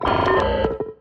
UIData_Computer Negative Message.wav